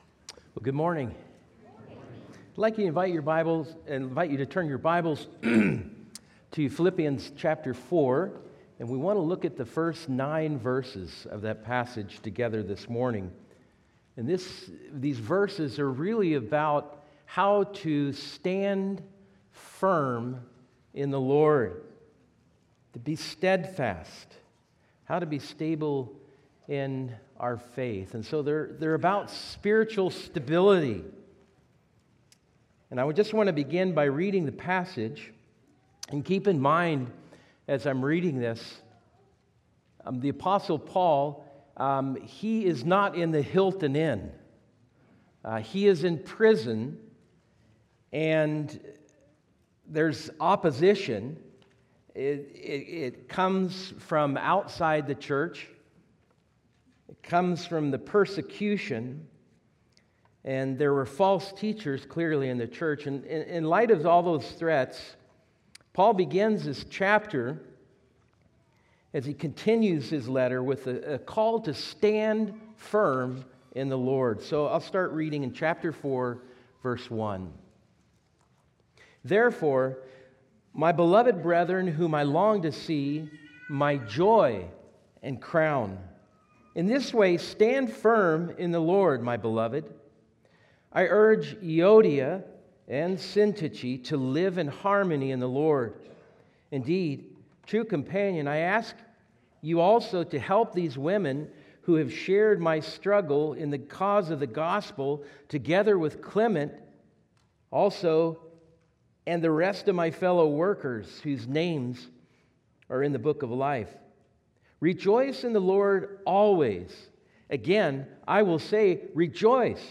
Sermons | New Hope Baptist Church